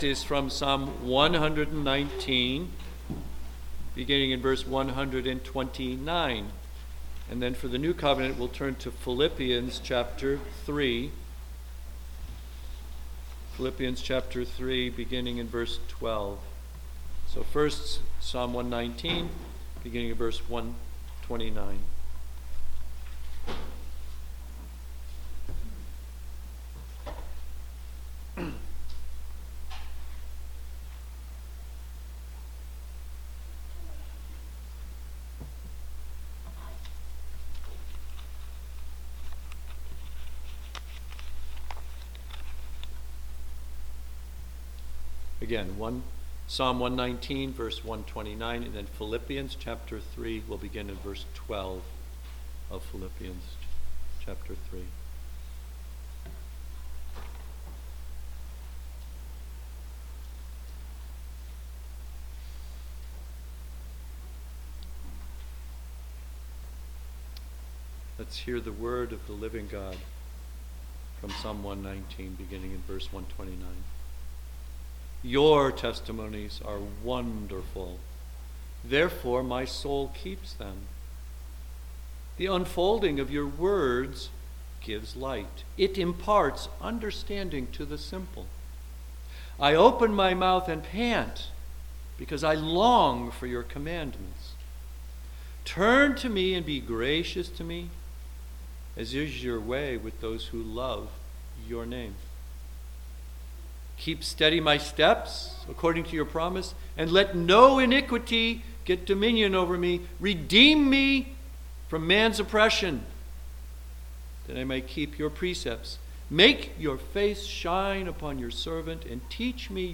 A Sermon from Psalm 119:129–136
Service Type: Sunday Morning